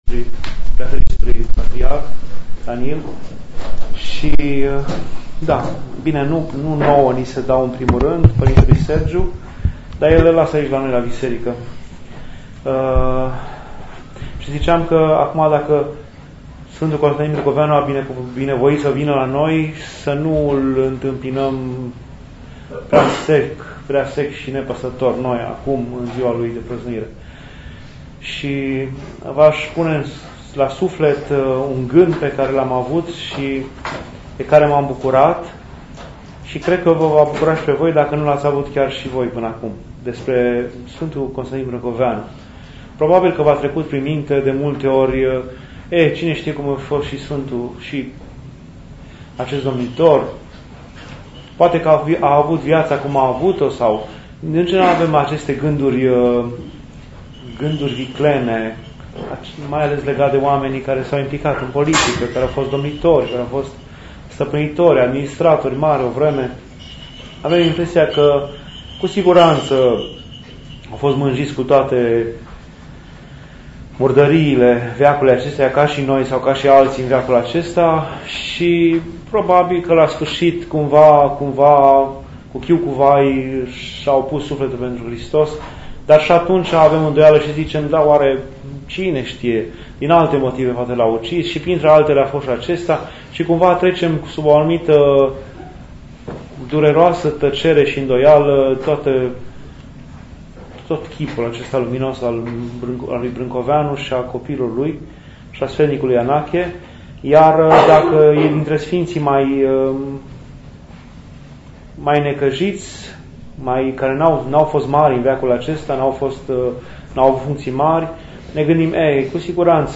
Dumnezeiasca Liturghie